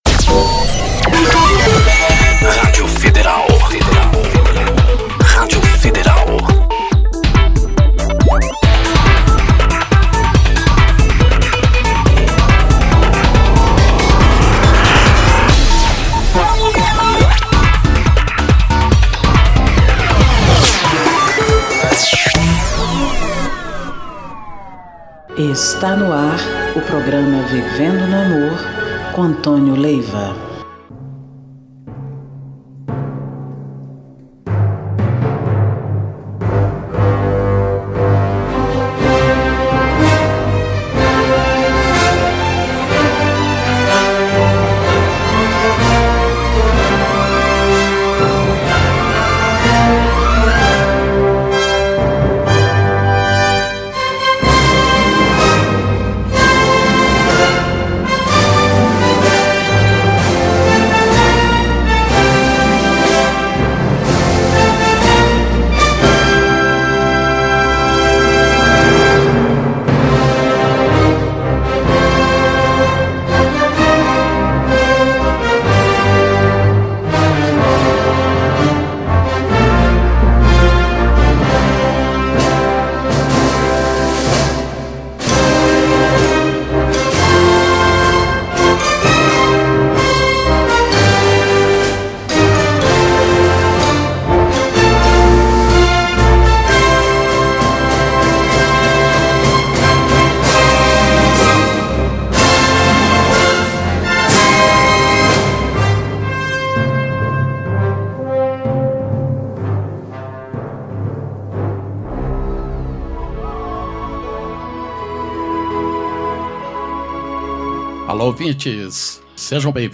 MÚSICAS E MENSAGENS